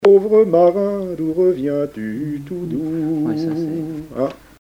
Chansons traditionnelles et témoignages
Pièce musicale inédite